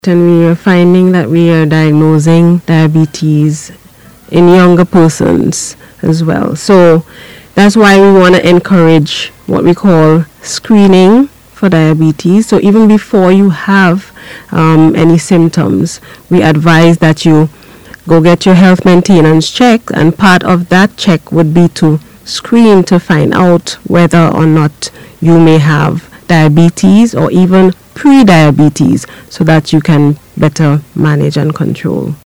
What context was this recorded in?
Speaking on NBC Radio